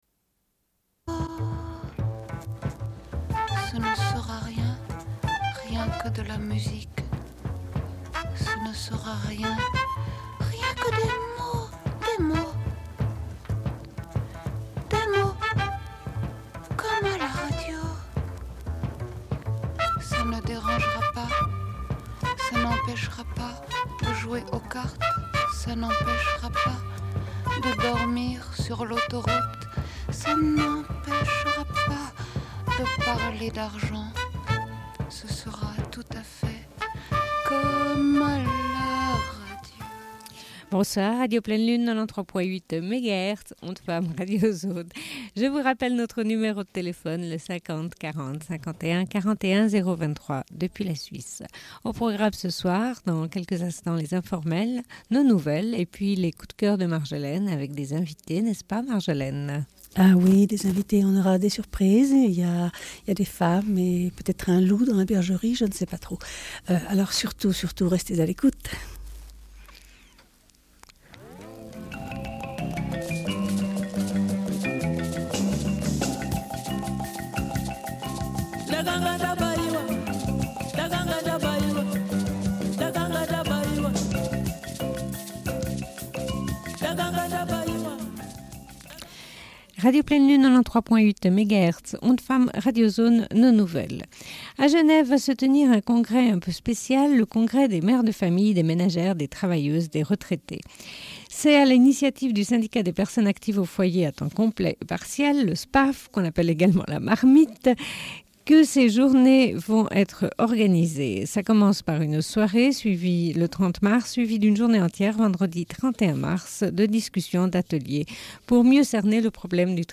Bulletin d'information de Radio Pleine Lune du 29.03.1995
Une cassette audio, face B